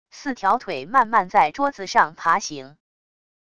四条腿慢慢在桌子上爬行wav音频